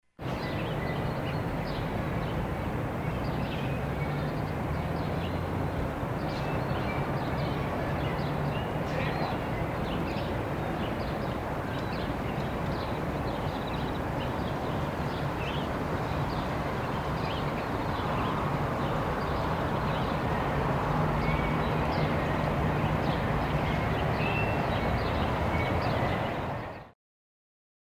SFX – CITY PARK AMBIENCE
SFX-CITY-PARK-AMBIENCE.mp3